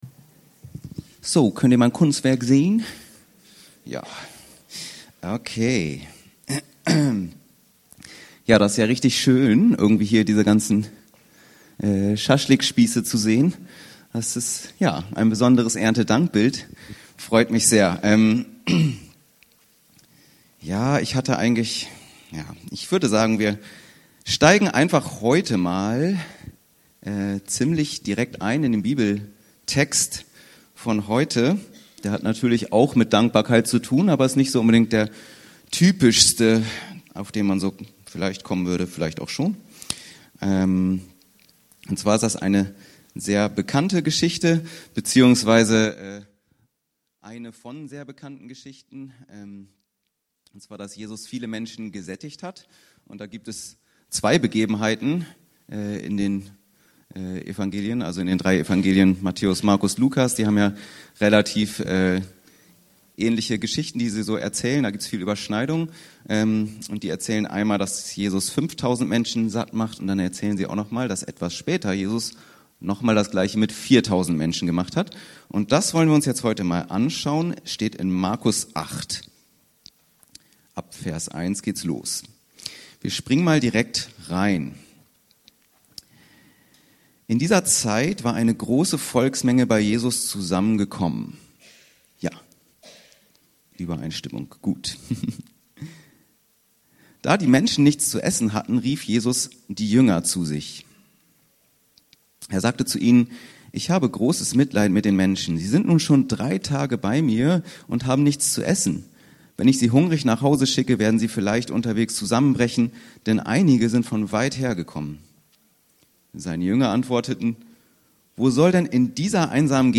Tatsächlich Dankbar- Markus 8,1-9 ~ Anskar-Kirche Hamburg- Predigten Podcast